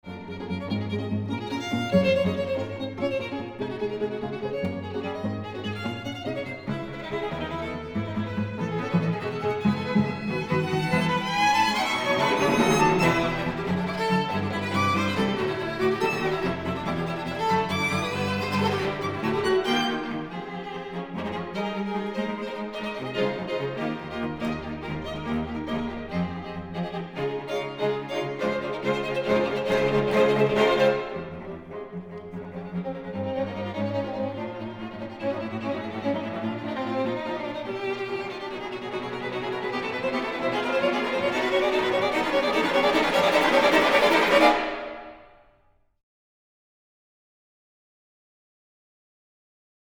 Recording venue: Westvest Church, Schiedam